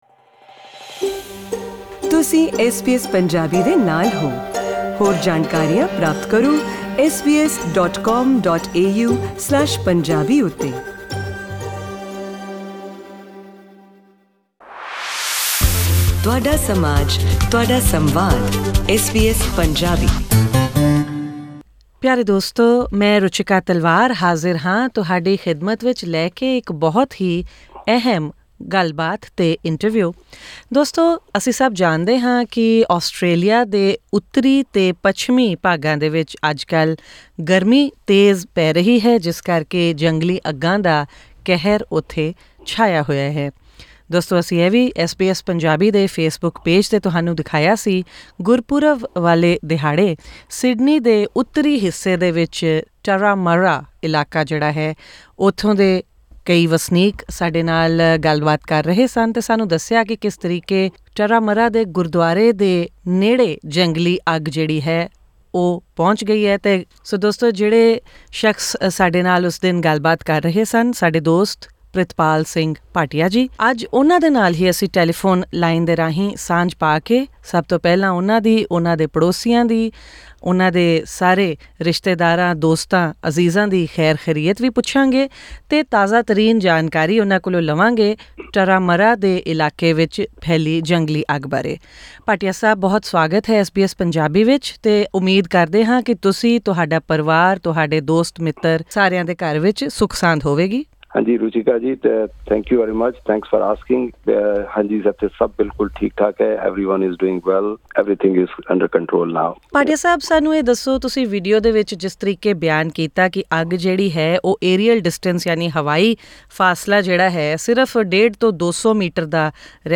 In what is being seen as one of Australia’s worst bushfire seasons, leading to an emergency being declared in New South Wales, the raging fires have been explained as “astonishing” and “scary” by a Sydney-based Indian-Australian. In an interview with SBS Punjabi over the phone